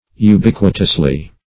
Search Result for " ubiquitously" : The Collaborative International Dictionary of English v.0.48: Ubiquitous \U*biq"ui*tous\ ([-u]*b[i^]k"w[i^]*t[u^]s), a. [See Ubiquity .]
ubiquitously.mp3